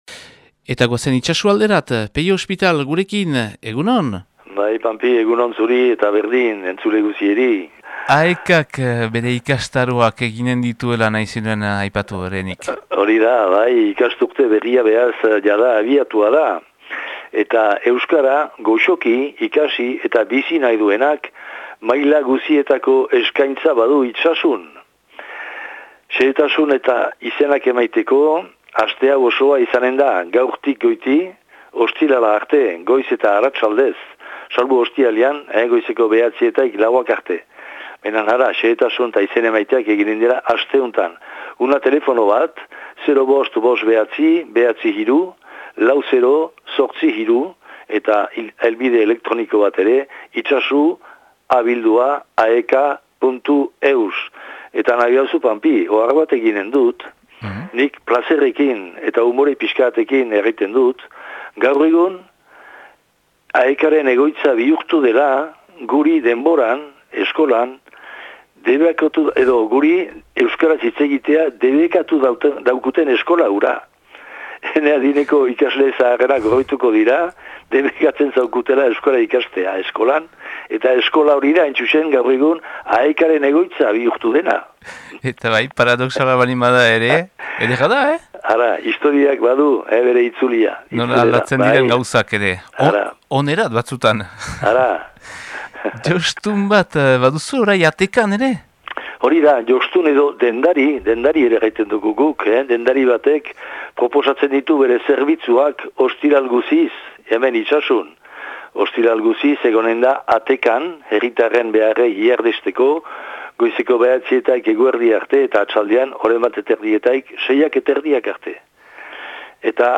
Irailaren 16ko Itsasuko berriak